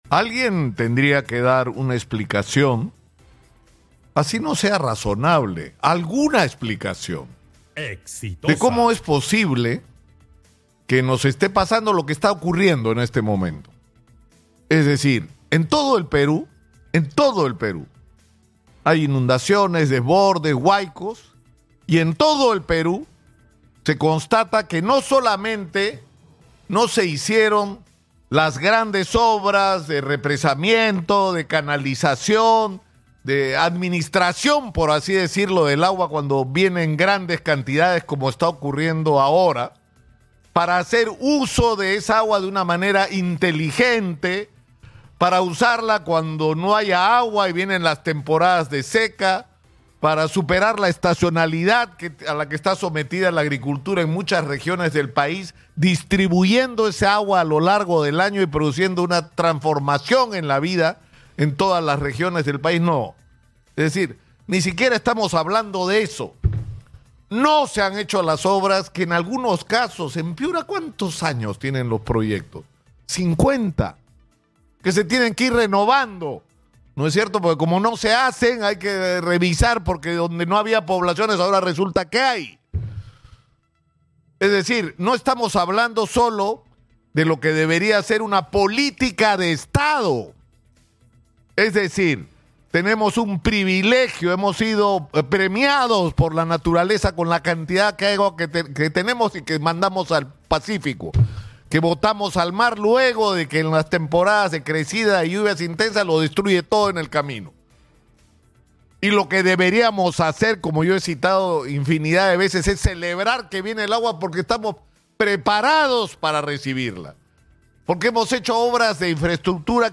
El periodista de Exitosa, Nicolás Lúcar, cuestionó la concesión del puente de Chancay a la empresa Norvial a raíz de la tragedia en el puente de Chancay.